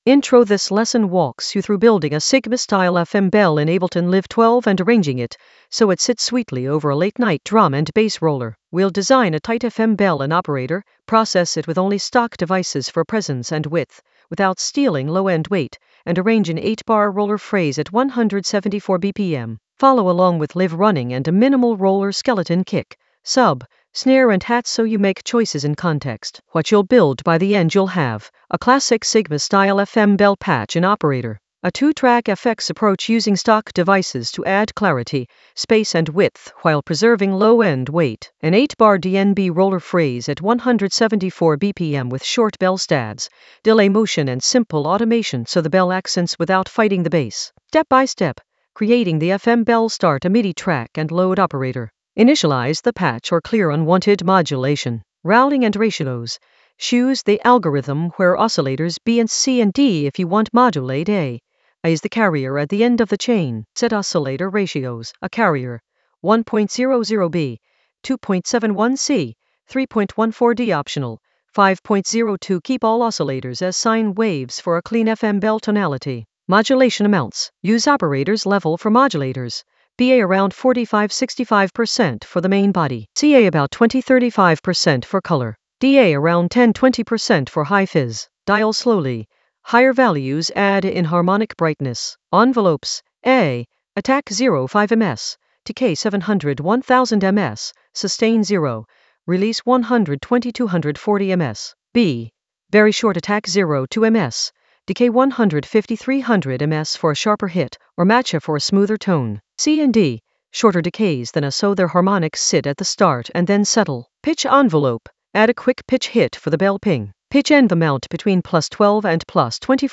An AI-generated intermediate Ableton lesson focused on Sigma FM bell: shape and arrange in Ableton Live 12 for late-night roller weight in the FX area of drum and bass production.
Narrated lesson audio
The voice track includes the tutorial plus extra teacher commentary.